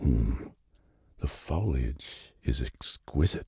B_foliage.ogg